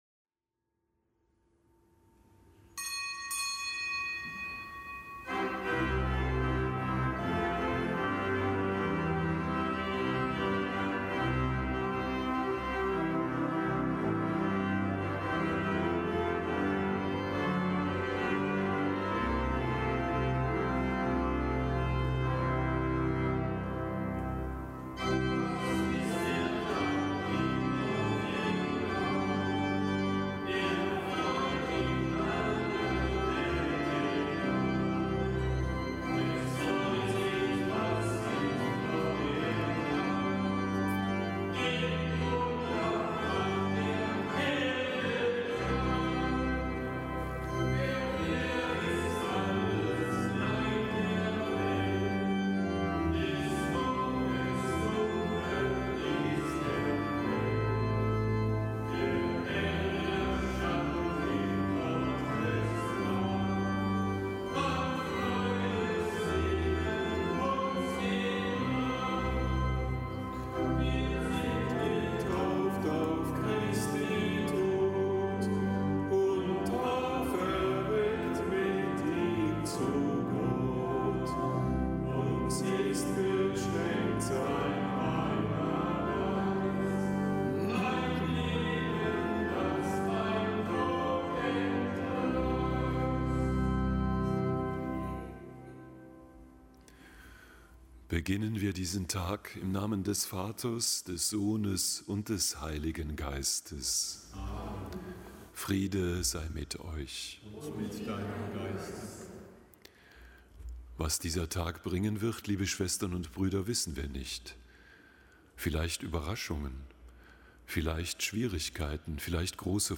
Kapitelsmesse aus dem Kölner Dom am Mittwoch der zweiten Osterwoche. Zelebrant: Weihbischof Ansgar Puff.